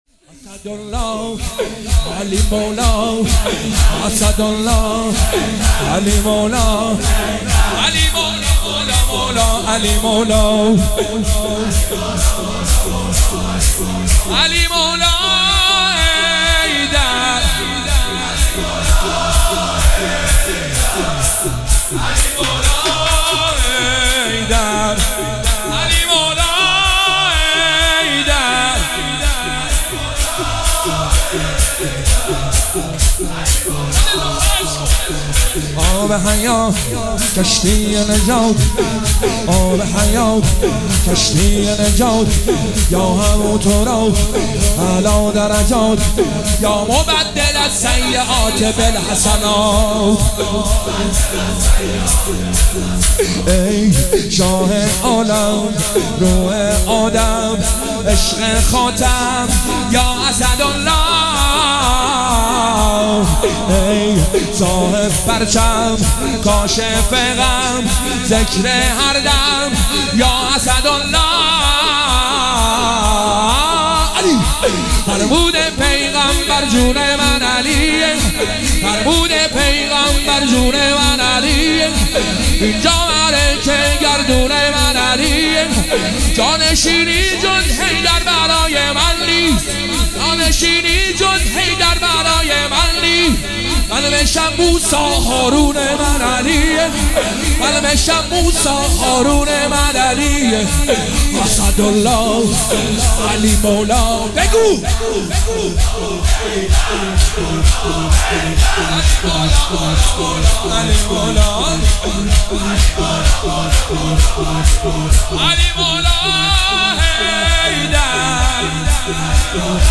مداحی شور فاطمیه
هیئت هفتگی 26 آبان 1403